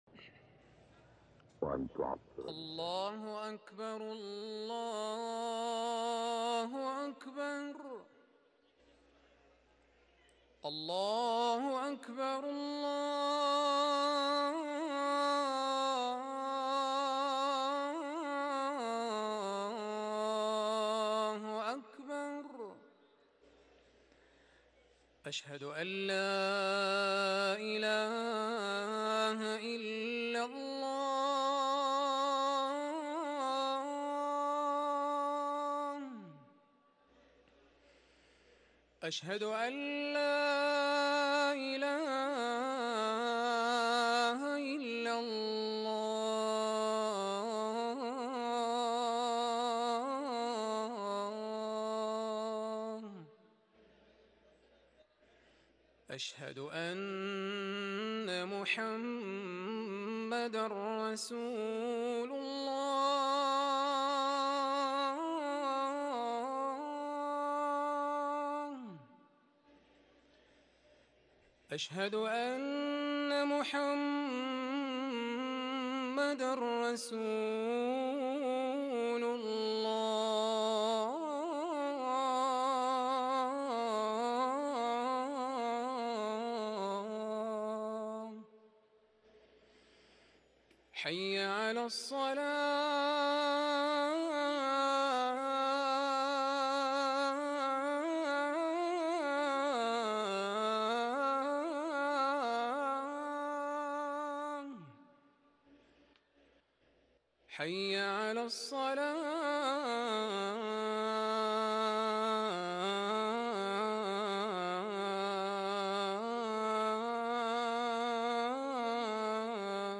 أذان العشاء
ركن الأذان